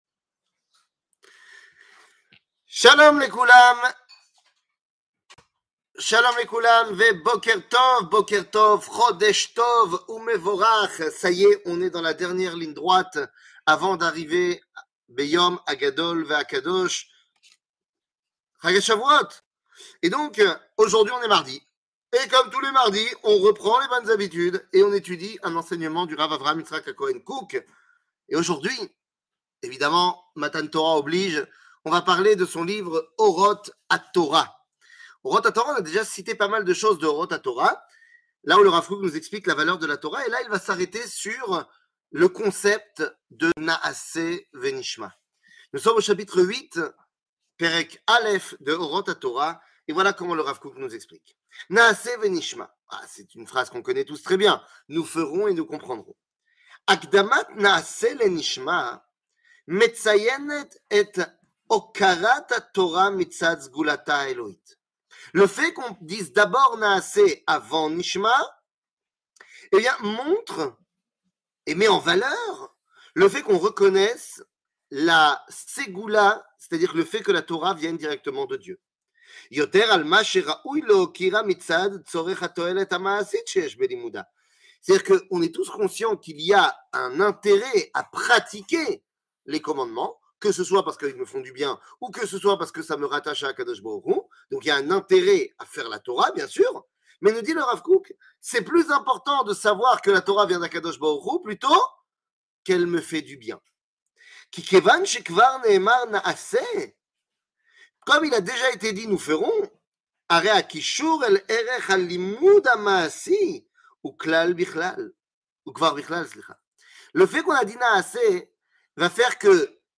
שיעור מ 31 מאי 2022